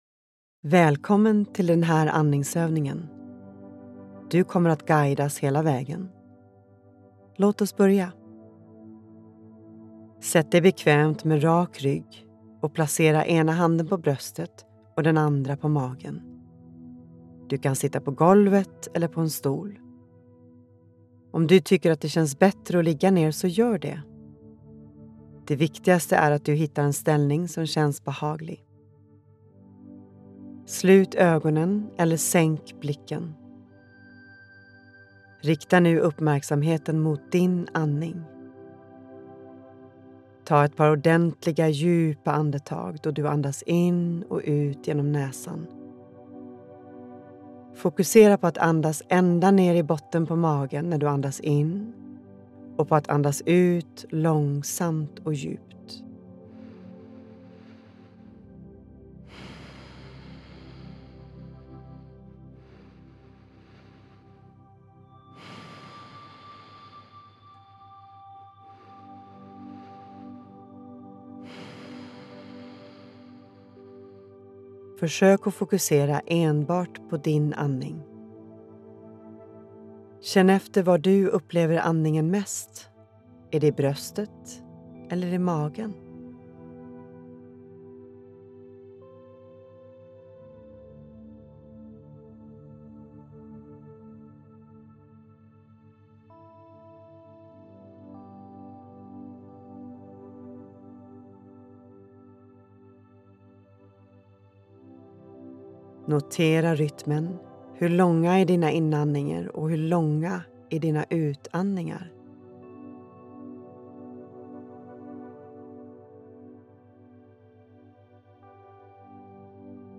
Den medvetna andningen – andningsövningar med ljudguide
• Du börjar med att få en introduktion till andningsövningen.
• Därefter får du 3 min för att fortsätta övningen i ditt eget tempo.